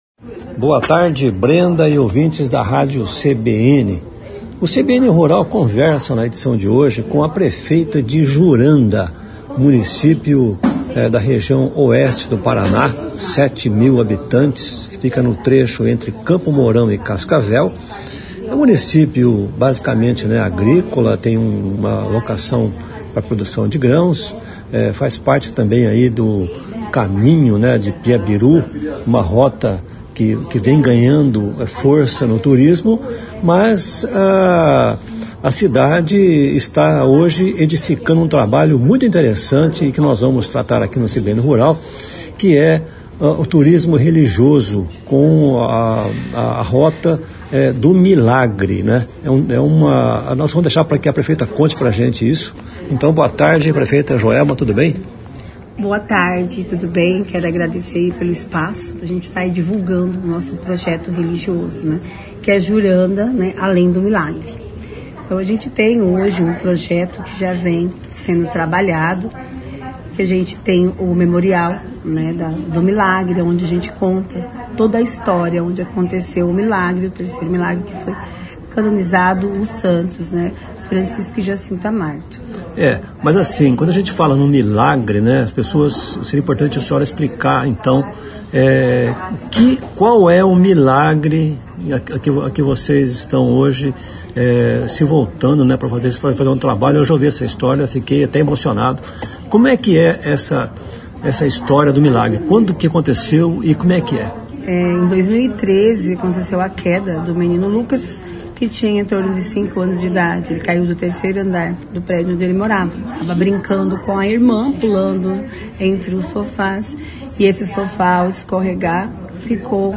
conversa com com Joelma Demeneque, prefeita de Juranda.